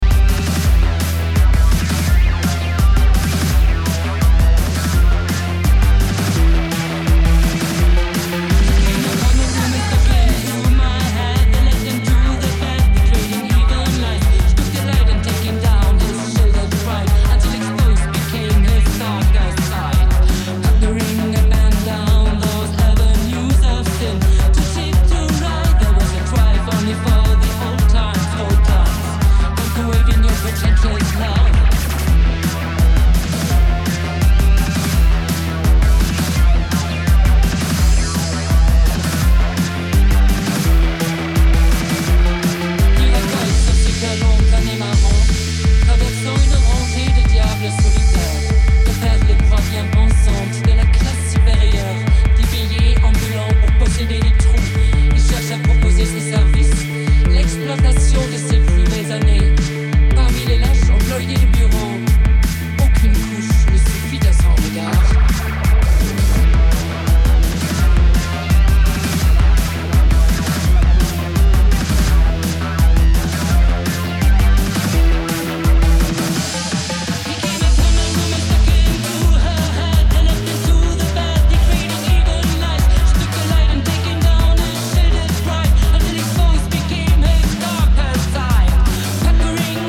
Pioneers of post-punk and the goth-wave despite themselves.